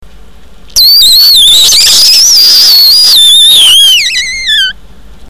Pets And Animals Ringtones